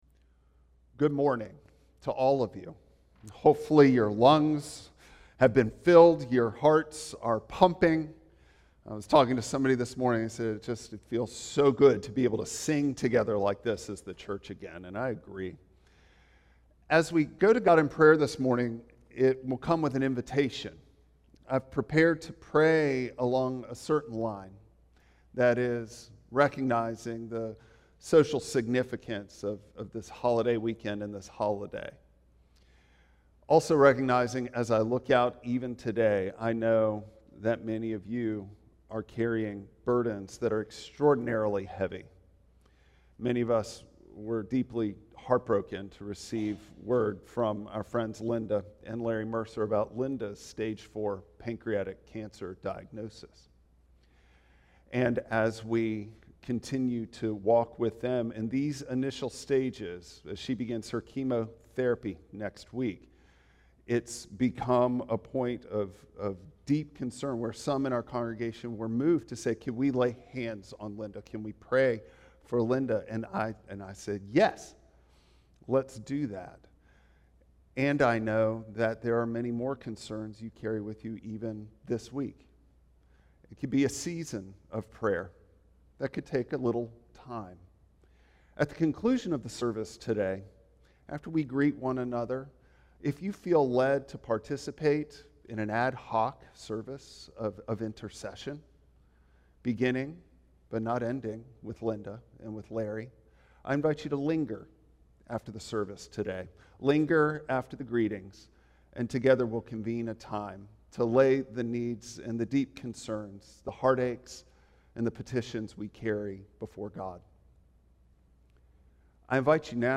Isaiah 58:6-12 Service Type: Traditional Service Bible Text